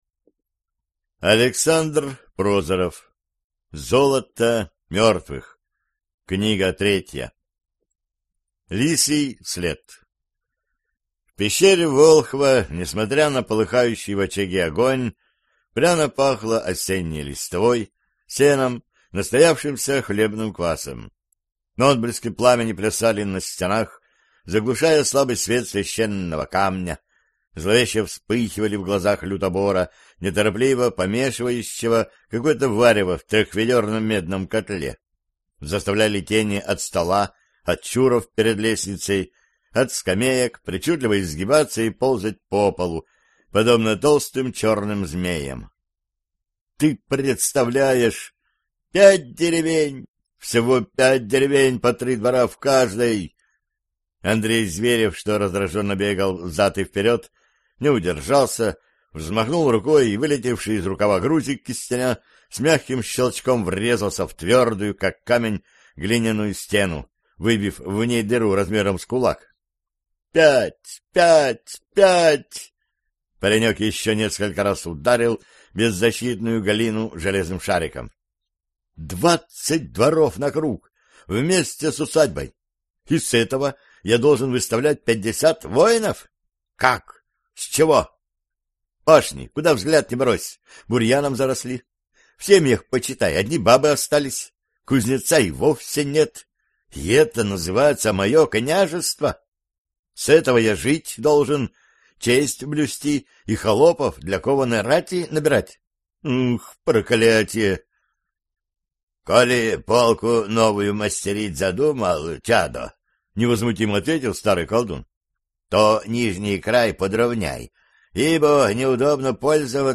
Аудиокнига Золото мертвых | Библиотека аудиокниг